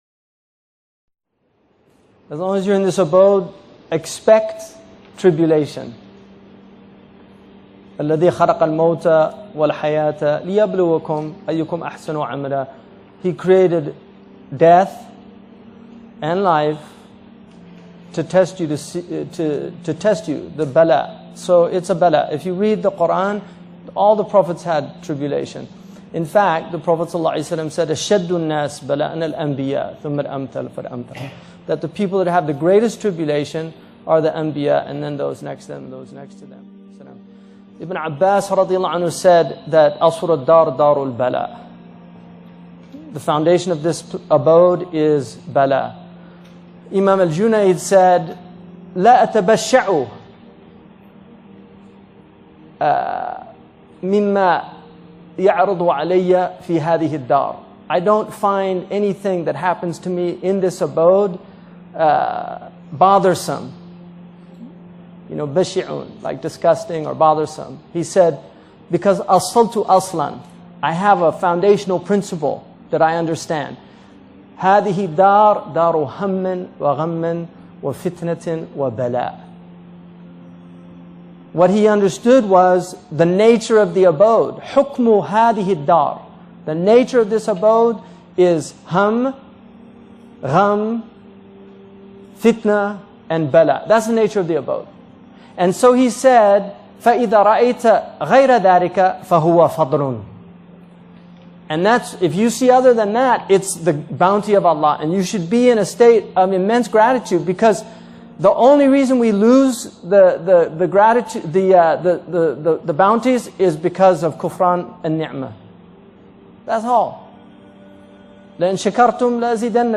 A Motivational Speech by Hamza Yusuf.mp3